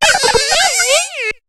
Cri d'Apitrini dans Pokémon HOME.